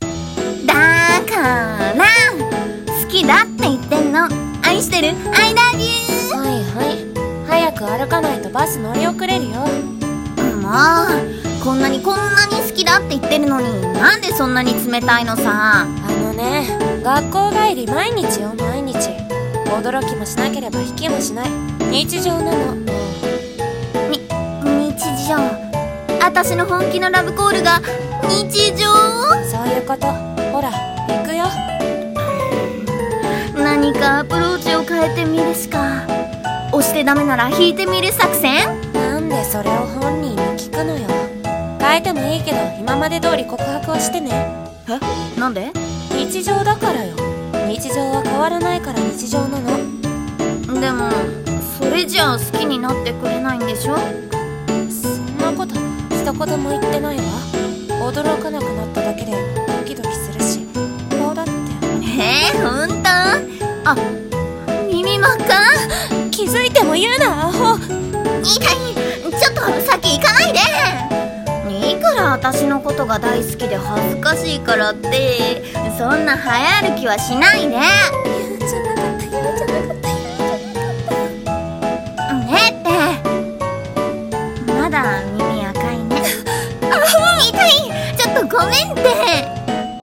声劇台本【特別じゃない帰り道】